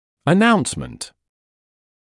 [ə’naunsmənt][э’наунсмэнт]объявление; извещение, уведомление